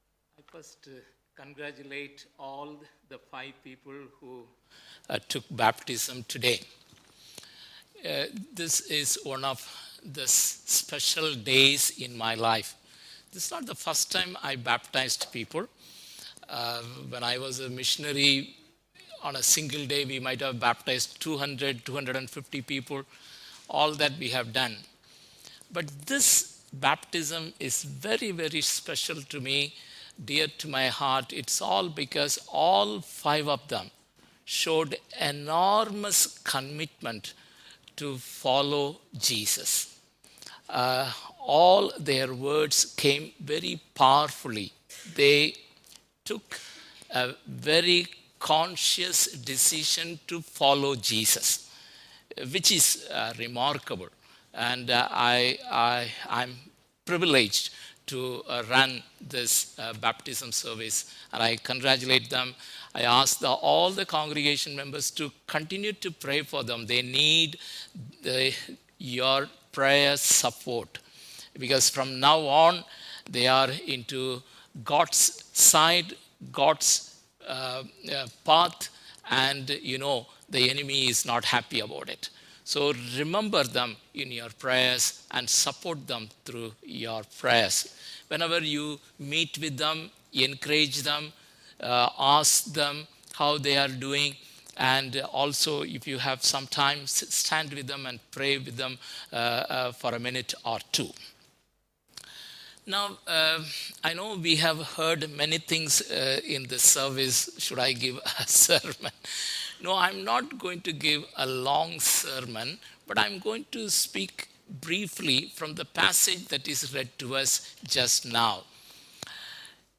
Sunday at Trinity was nothing short of extraordinary as our congregation gathered for a deeply moving service.